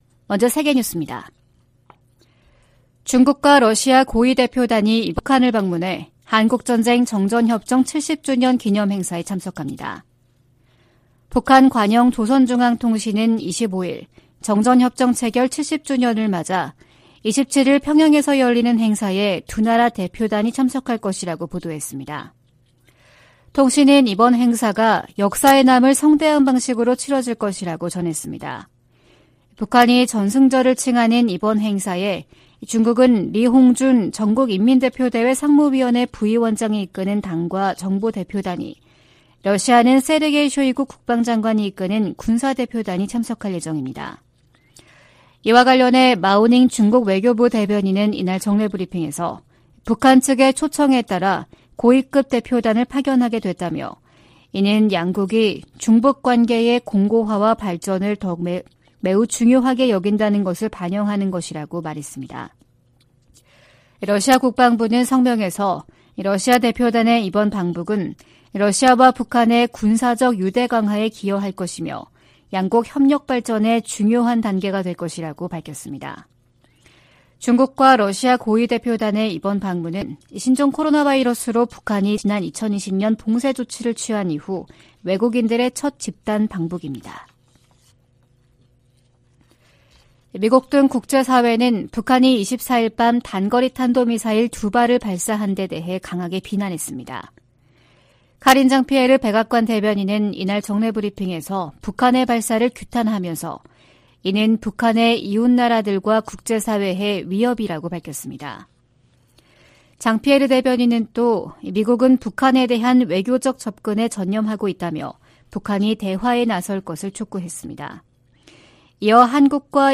VOA 한국어 '출발 뉴스 쇼', 2023년 7월 26일 방송입니다. 백악관과 미 국무부는 월북 미군과 관련해 여전히 북한의 응답을 기다리는 중이며, 병사의 안위와 월북 동기 등을 계속 조사하고 있다고 밝혔습니다. 북한이 24일 탄도미사일 2발을 동해상으로 발사했습니다.